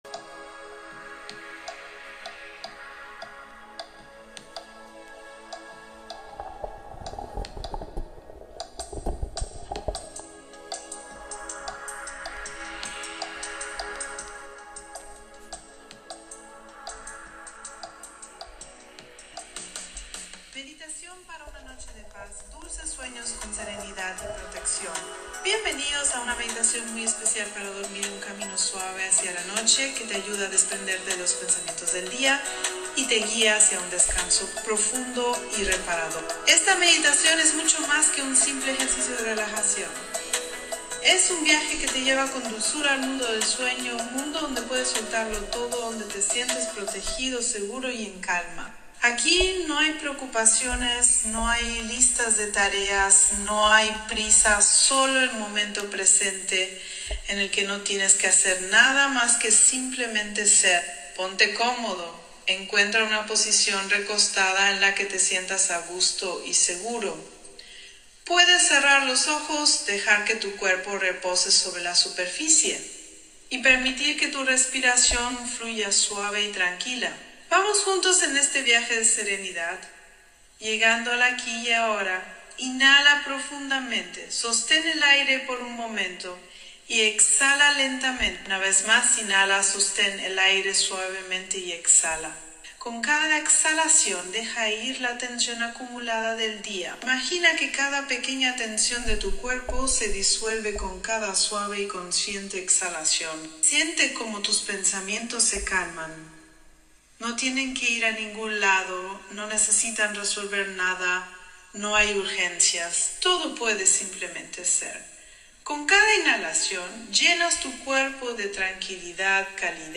Meditación para una Noche de Paz – Dulces Sueños con Serenidad